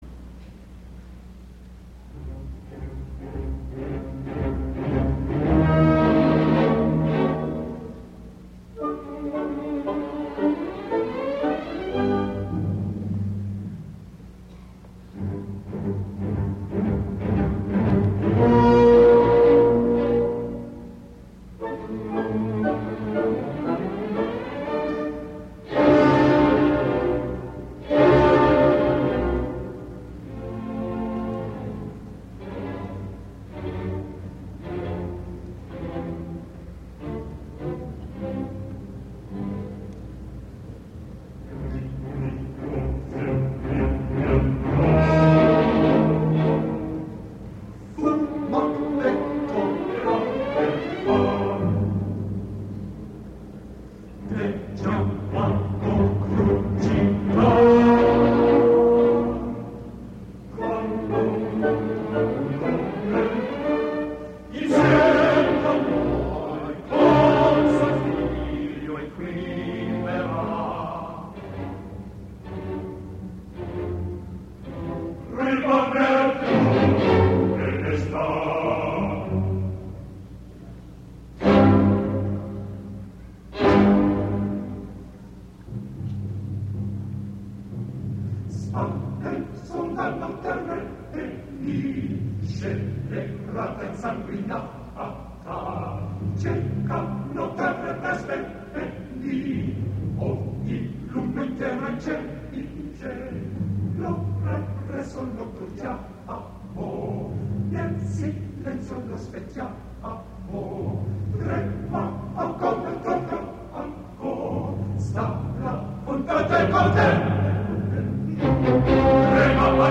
Chicago Symphony Chorus, dir. James Levine (1981)